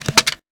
weapon_foley_pickup_17.wav